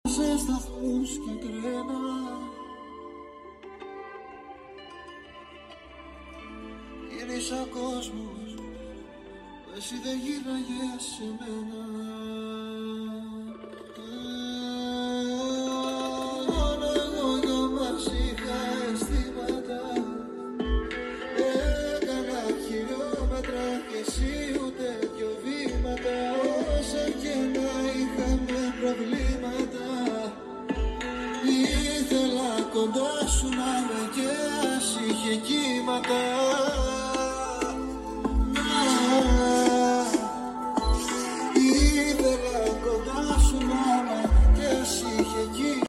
70% ένταση στο στρώσιμο.VOLUME UP 🎤.